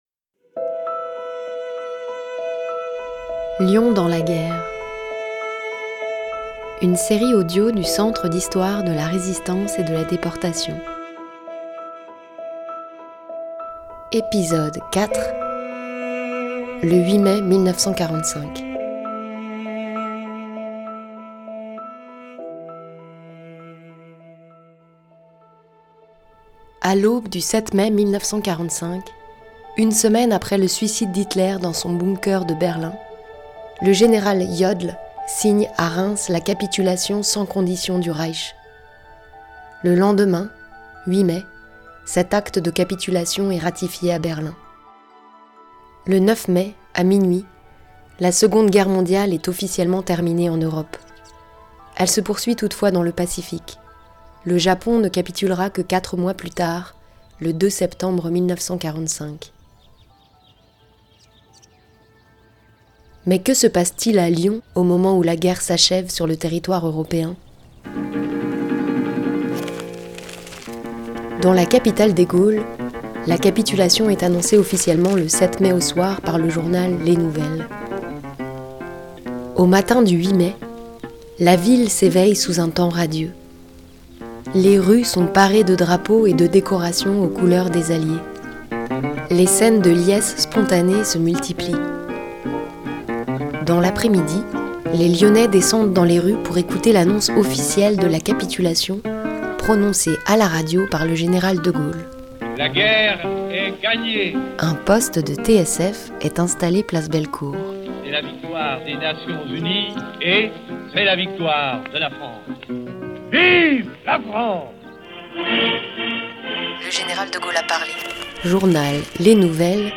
La série documentaire Lyon dans la guerre retrace l’histoire de la Seconde guerre mondiale à Lyon, mise en relief par les témoignages de ceux qui l’ont vécue. Depuis les années 90, le CHRD collecte la parole d’anciens résistants et déportés.